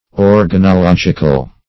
organological - definition of organological - synonyms, pronunciation, spelling from Free Dictionary
Organological \Or`ga*no*log"ic*al\, a. Of or relating to organology.